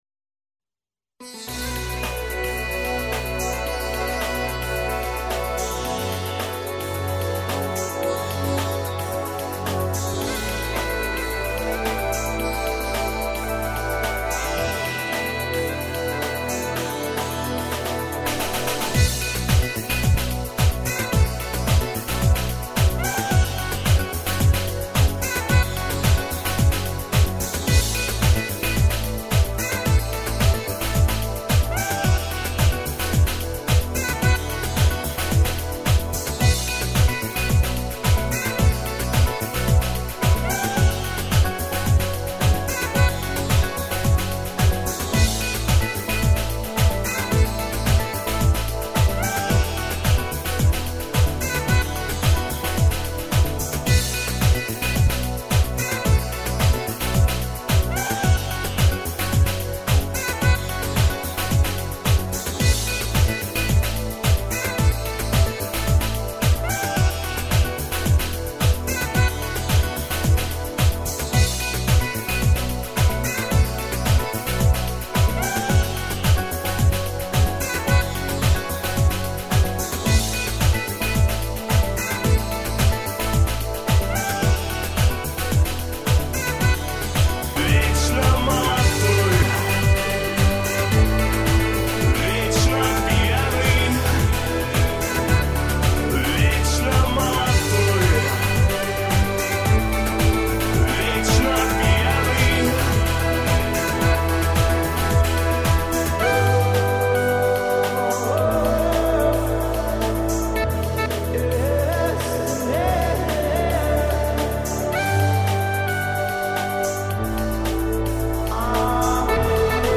минусовка версия 239344